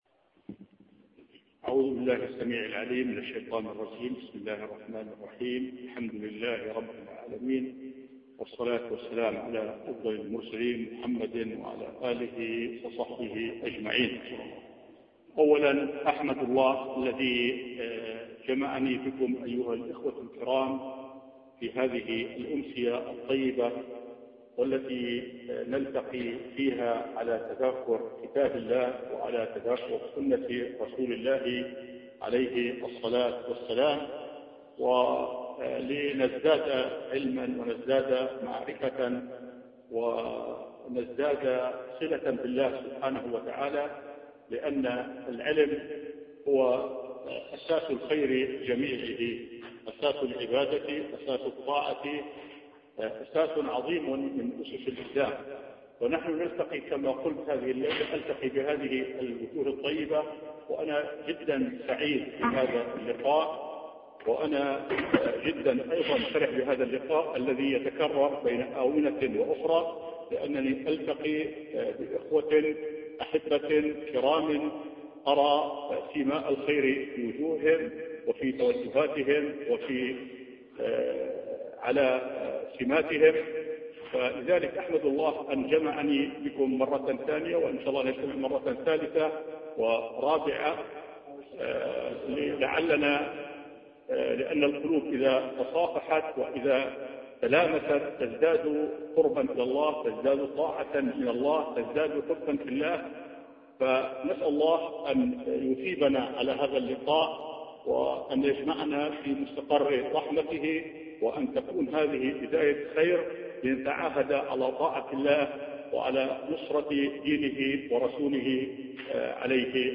محاضرة صوتية